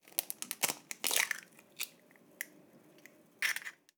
Cascar un huevo
cáscara
chasquido
Sonidos: Acciones humanas
Sonidos: Hogar